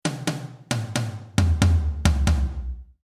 Concert-Tom-Sound
Eine Sonderstellung in punkto Sound nehmen die Concert-Toms ein.
open sustain controlled sustain hard attack concert-sound
dm_tm_concert.mp3